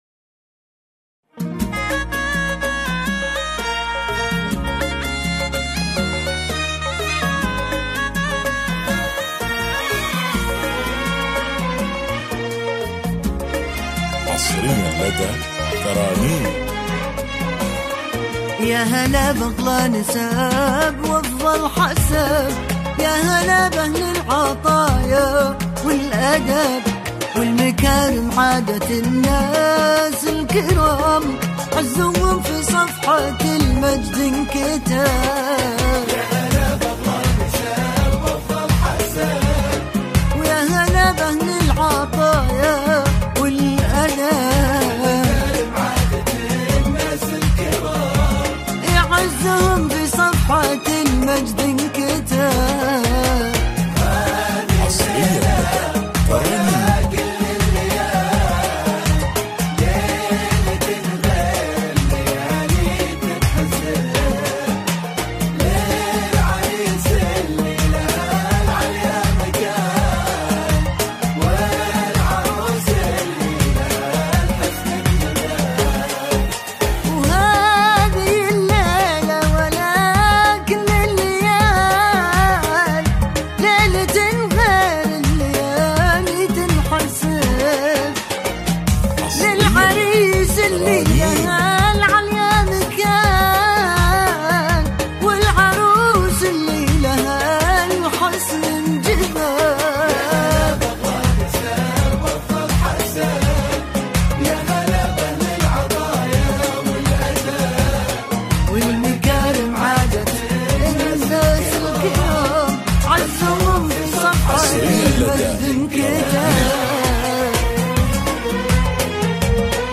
زفات
بدون موسيقى